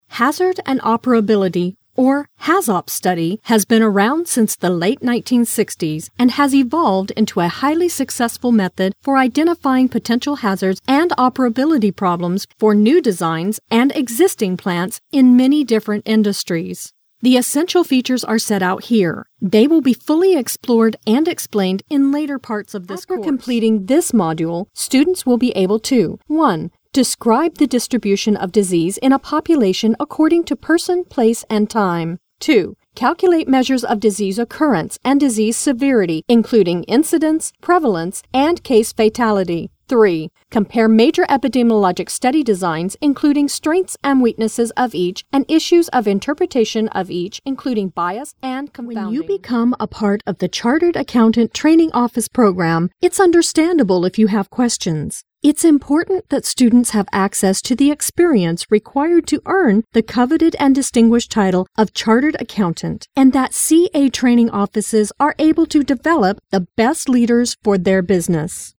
Voice Intonations: Bright, Energetic, Professional, Calming, Adult, Millennial, Child, Senior
englisch (us)
Sprechprobe: eLearning (Muttersprache):